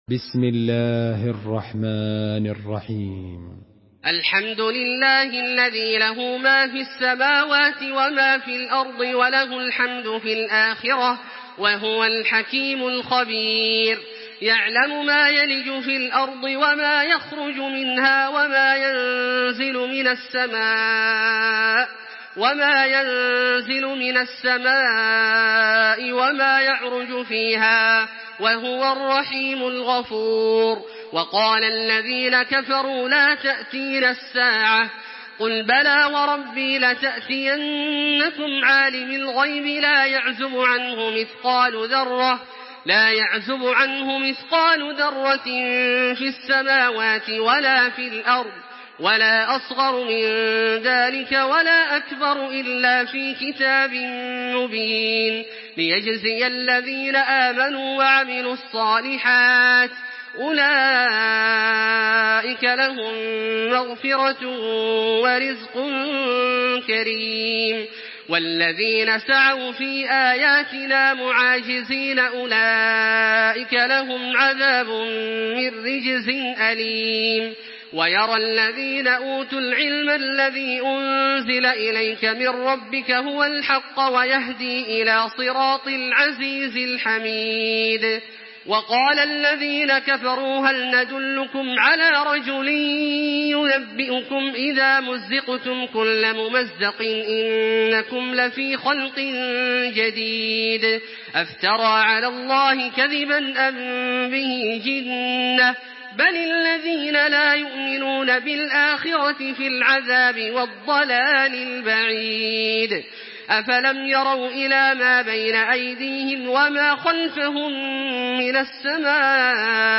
Surah সাবা MP3 by Makkah Taraweeh 1426 in Hafs An Asim narration.
Murattal Hafs An Asim